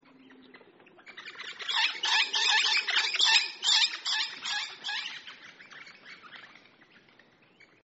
Voice: noisy chatter, bell-like call, raucous screech.
Call 2: screech while flying
Adel_Rosella_flying_scr.mp3